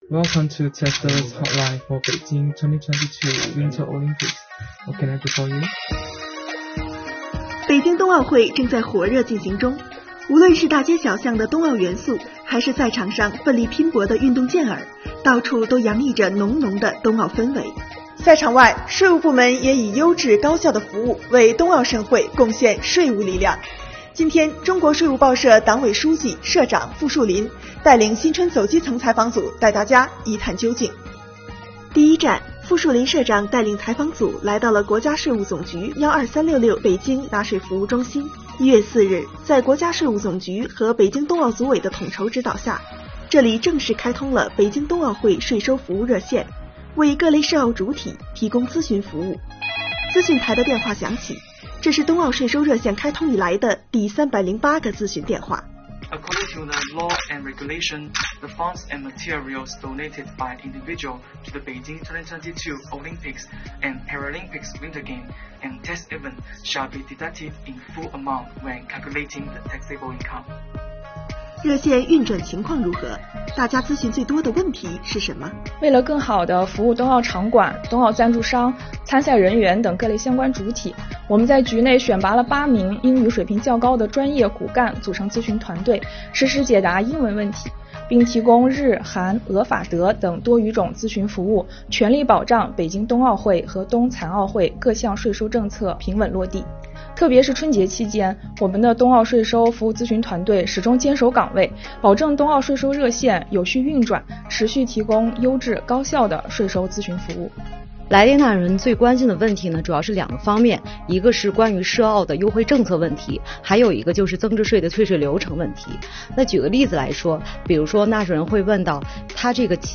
中国税务报社“新春走基层”采访组走进12366北京中心。
国家税务总局12366北京纳税服务中心冬奥会税收服务热线话务间。
当记者结束采访离开时，冬奥税收热线电话仍在不时响起。